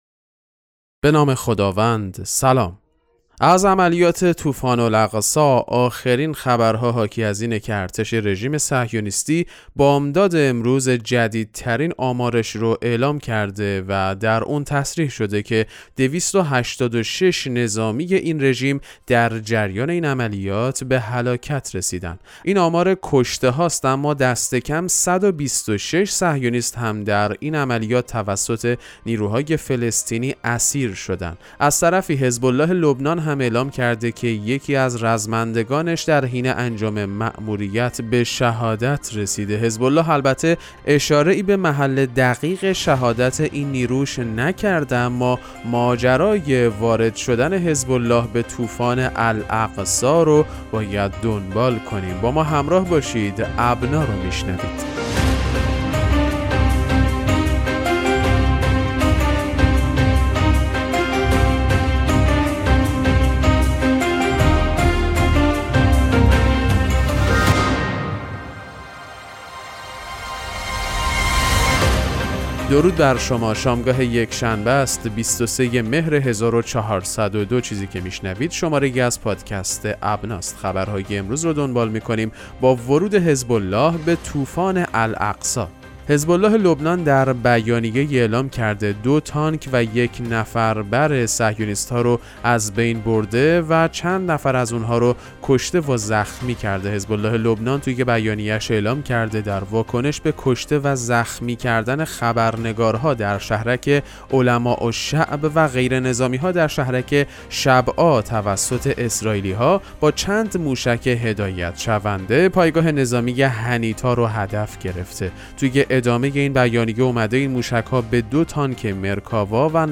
پادکست مهم‌ترین اخبار ابنا فارسی ــ 23 مهر 1402